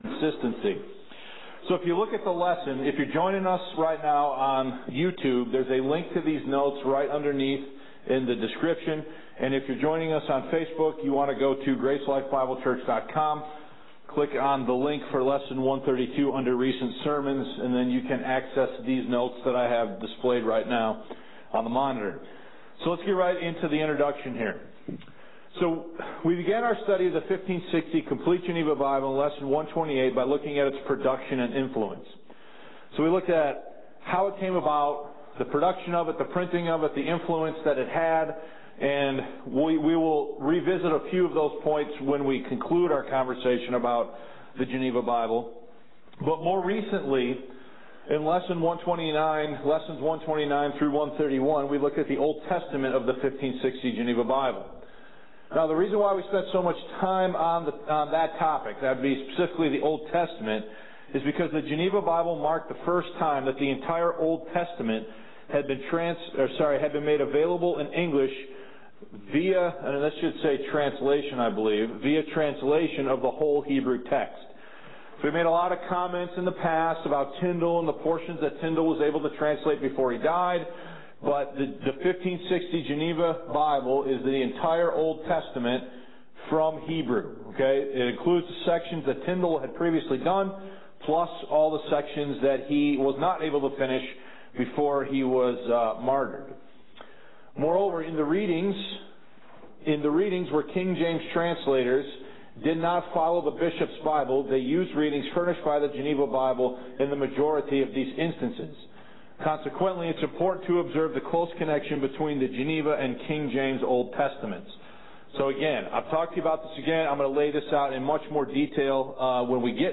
Lesson 132 The Complete Geneva Bible: The New Testament